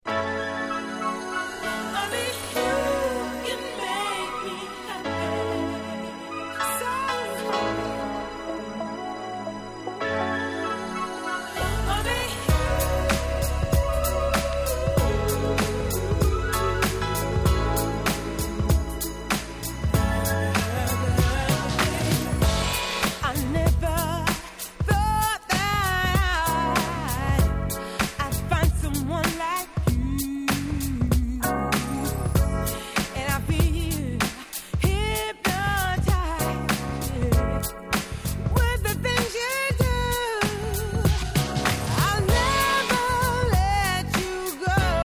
96' Nice Cover UK R&B !!!
御馴染みADMビートに透き通る様なメロディ！